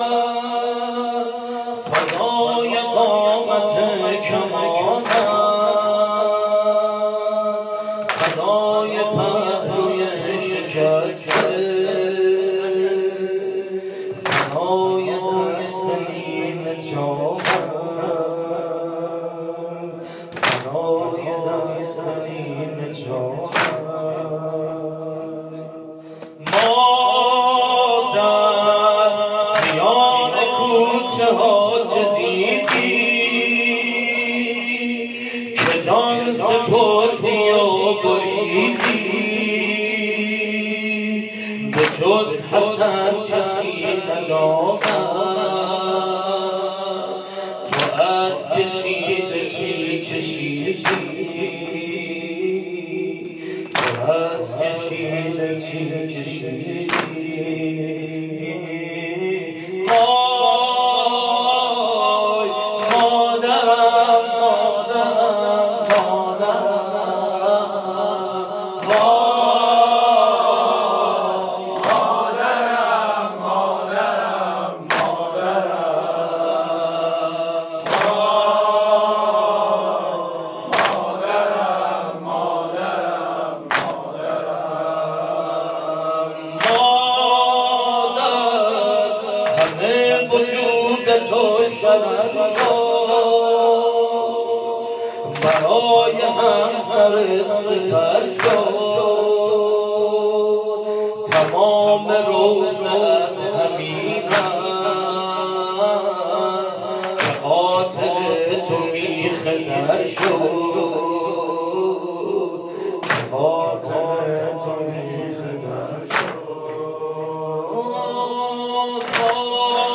خیمه گاه - هیئت حضرت ابوالفضل(ع)روستای ازناوه - واحد شب شهادت حضرت زهرا(س) 1395